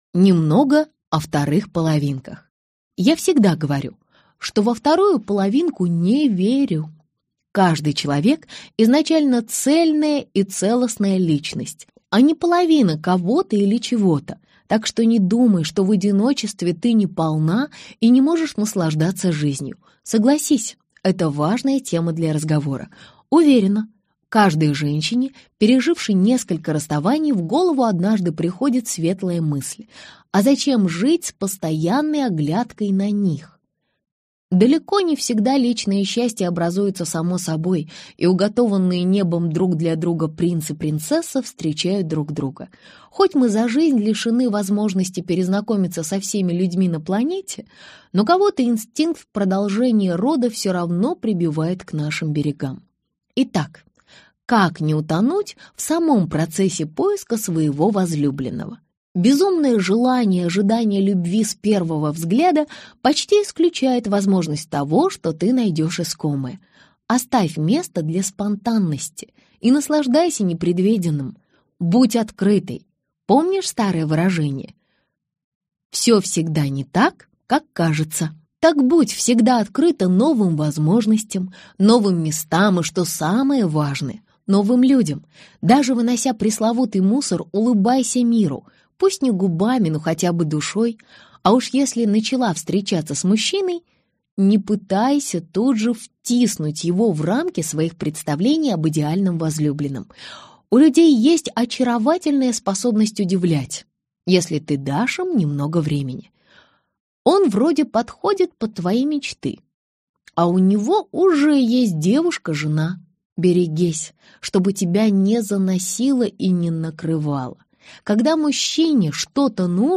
Аудиокнига И все-таки, почему на одних женятся, а других обходят стороной | Библиотека аудиокниг